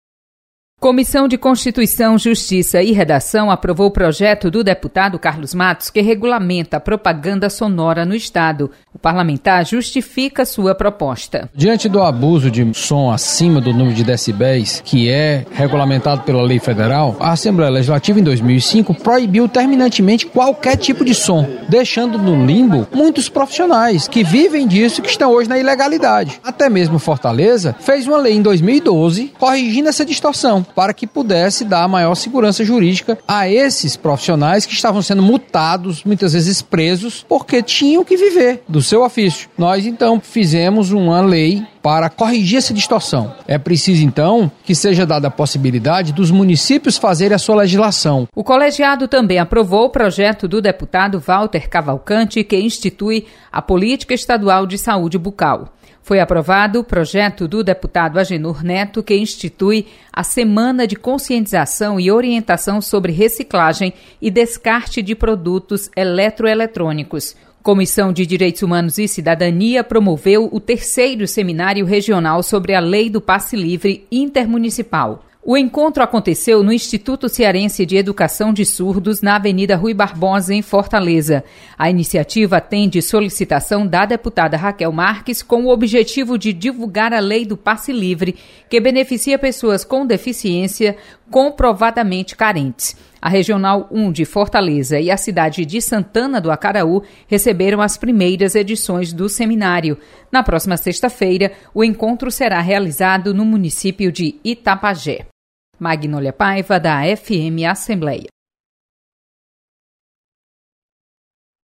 Acompanhe resumo das comissões técnicas permanentes da Assembleia Legislativa. Repórter